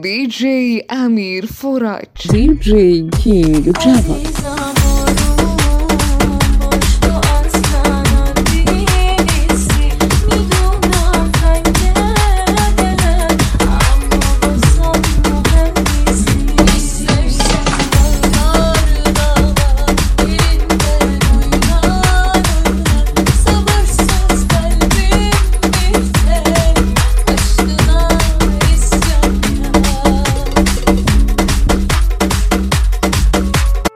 ریمیکس ترکی فارسی تند بیس دار